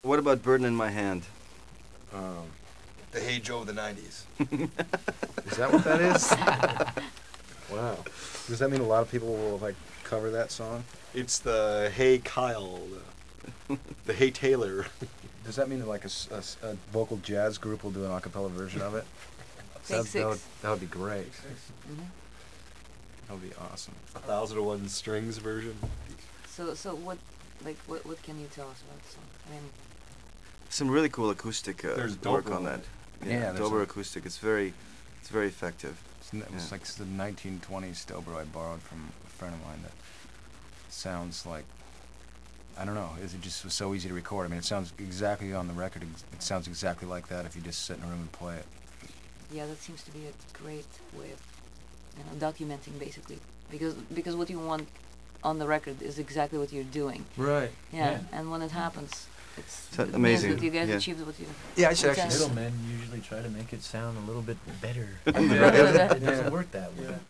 The band discusses "Burden In My Hand" 1,498,032